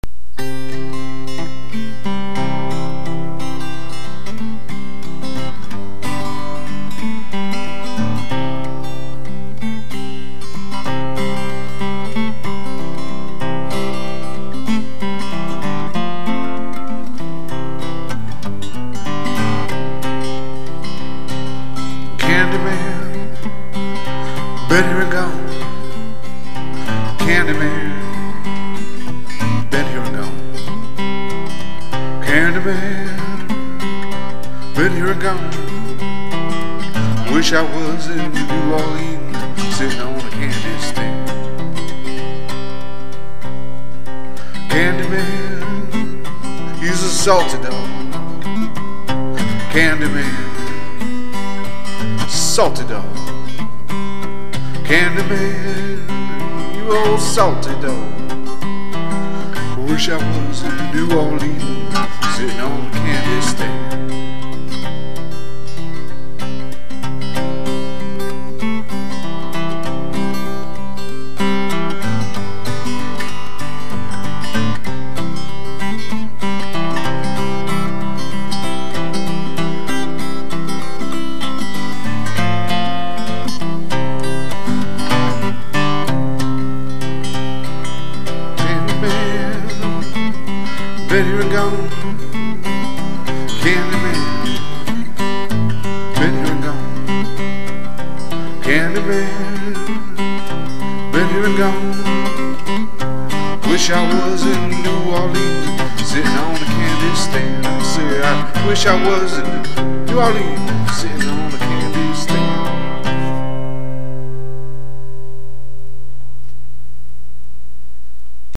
Solo Performances
Mostly Acoustic